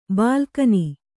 ♪ bālkani